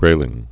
(grālĭng)